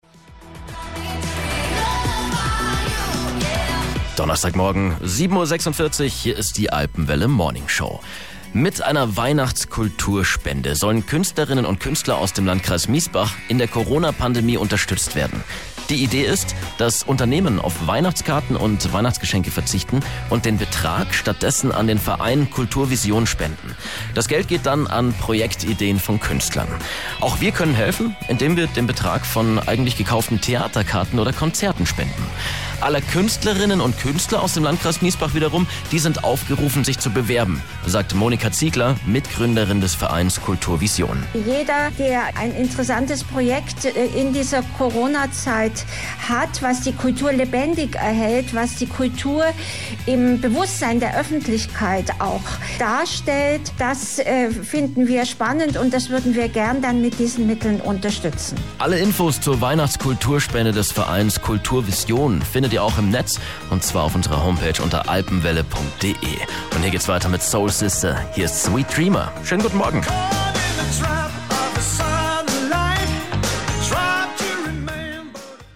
Ansage bei Alpenwelle am 10.12.2020: